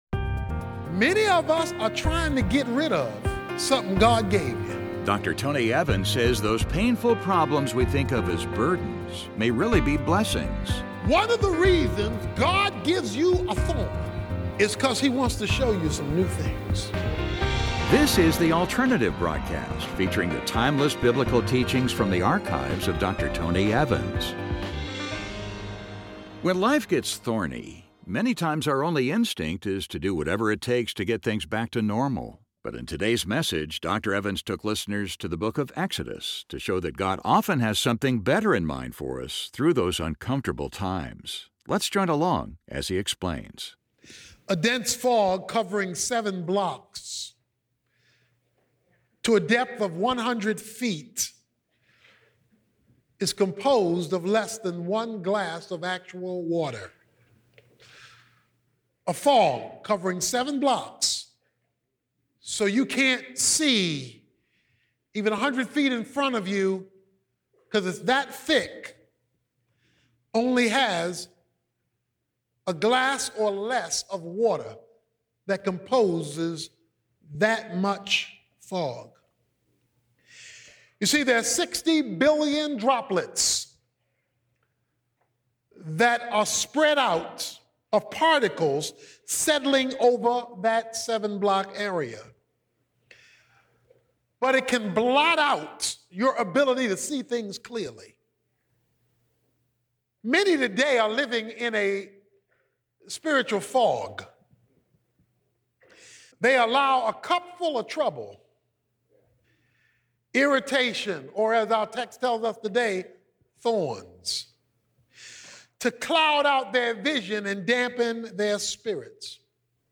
When life gets thorny, many times our only instinct is to do whatever it takes to get things back to normal. But in this message, Dr. Evans took listeners to the book of Exodus to show that God often has something better in mind for us through those uncomfortable times.